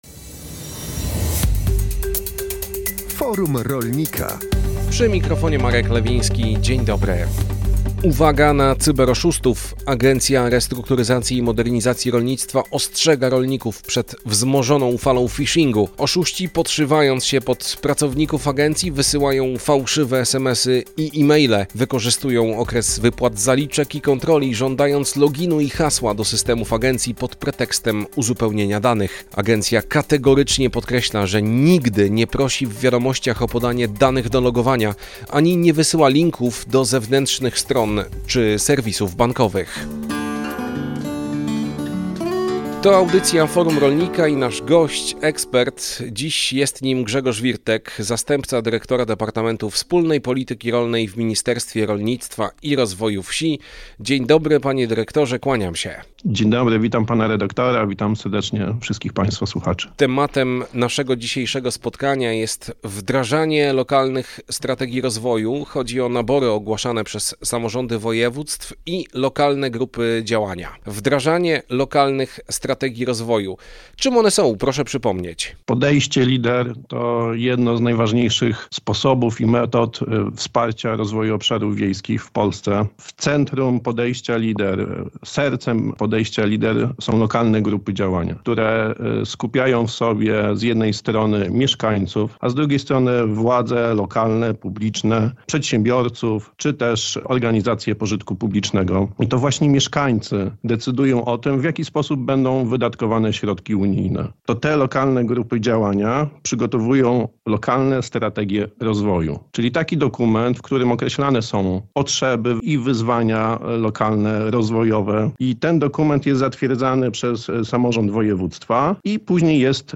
Audycja o tematyce rolnej „Forum Rolnika” emitowana jest na antenie Radia Kielce w środy po godz. 12.